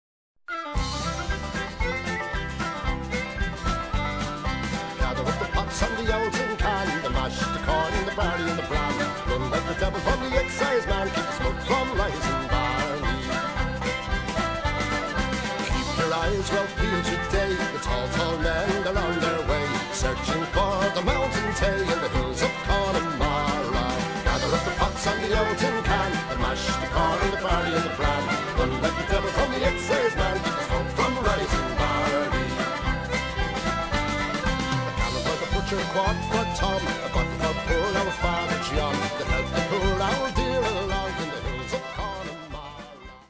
20 Collected Irish Pub Songs